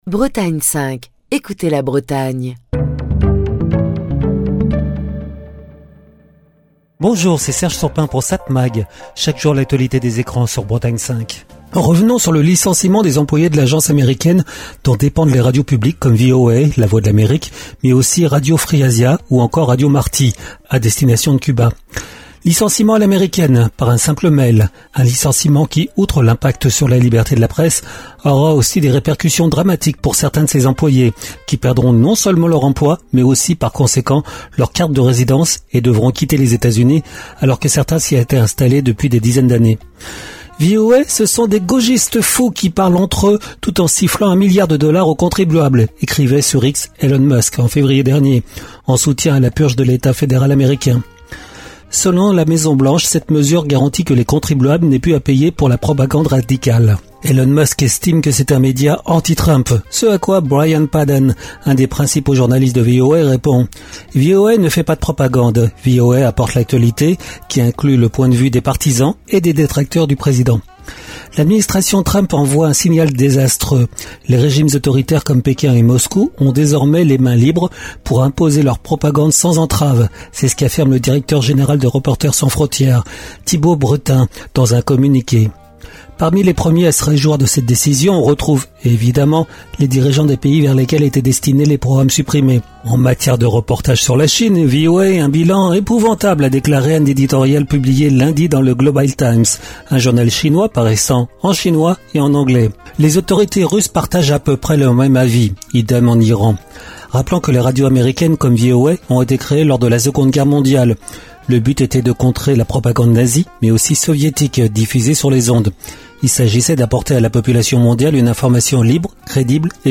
Chronique du 20 mars 2025. Quand Trump prend une décision qui réjouit les principaux dictateurs du monde, il y a de quoi s’inquiéter.